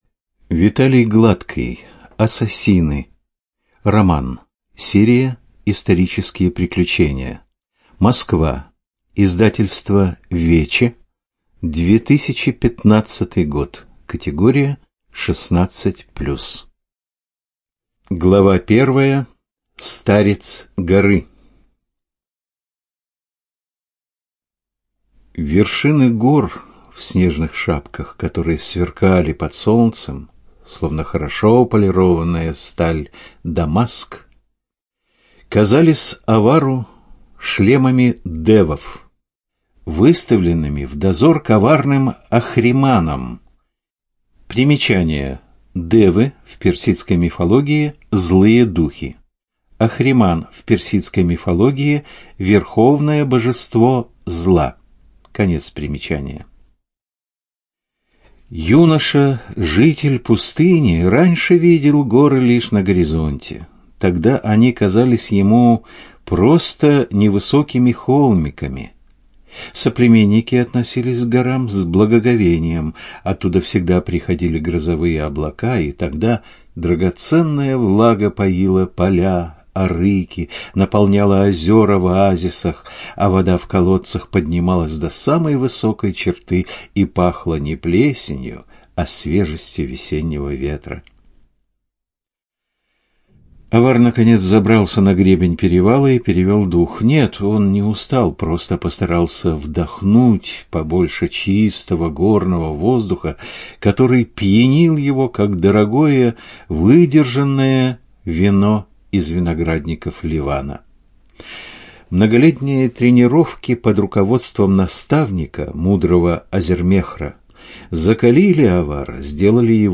ЖанрПриключения, Историческая проза
Студия звукозаписиЛогосвос